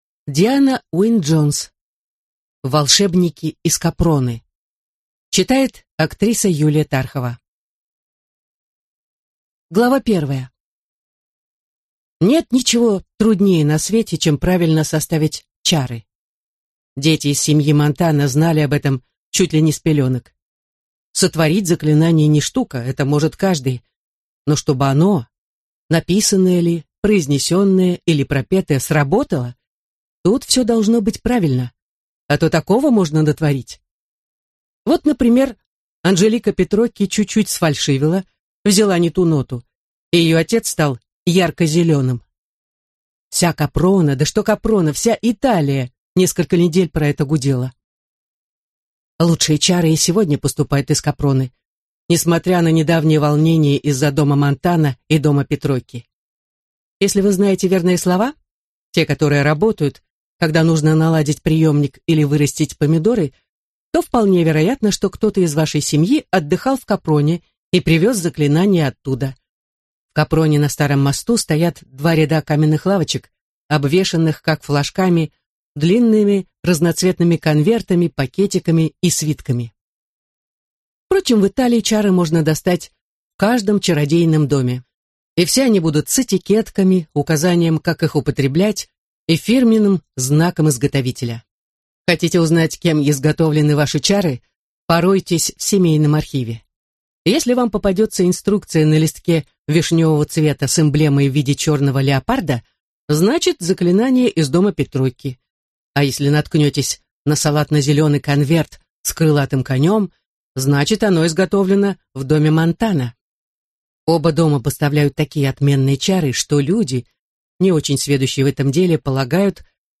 Аудиокнига Волшебники из Капроны | Библиотека аудиокниг